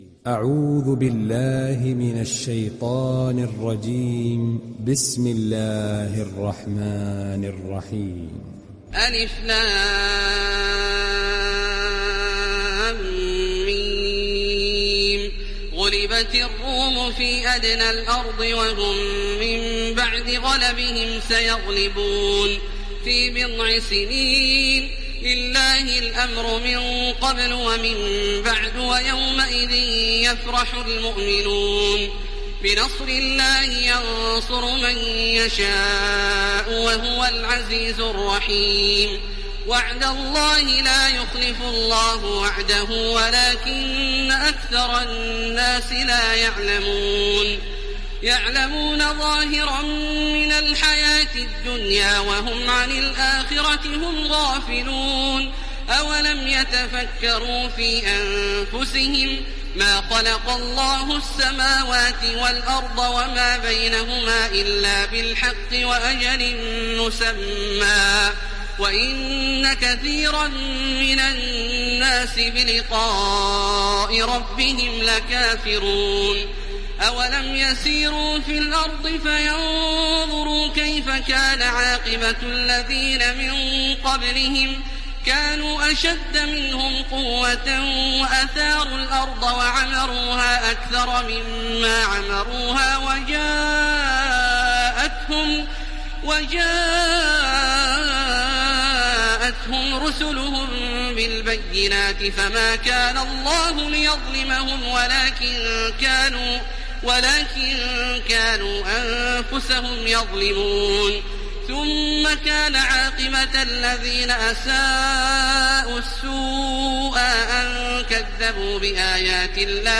Télécharger Sourate Ar Rum Taraweeh Makkah 1430